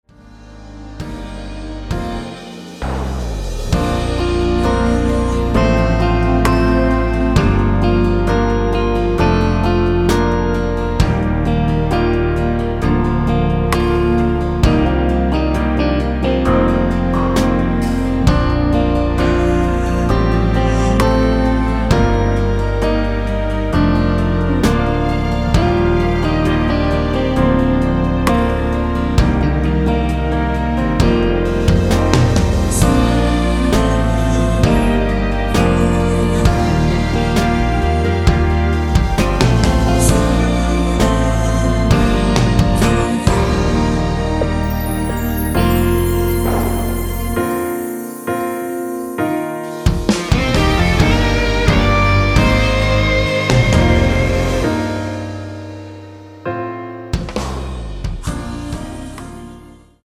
원키에서(-3)내린 코러스 포함된 MR입니다.(미리듣기 확인)
Bb